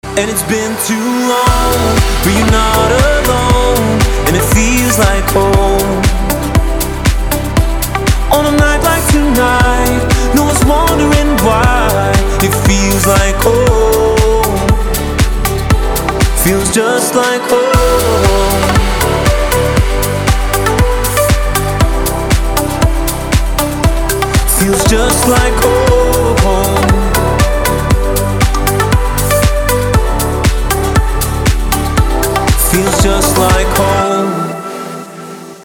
• Качество: 320, Stereo
мужской вокал
красивый мужской голос
dance
спокойные